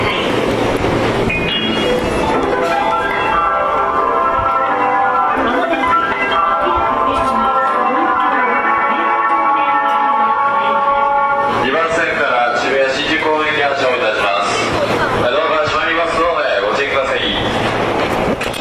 ただ高架下なのでうるさく、一部音質が悪いホームがあり、玉に瑕です。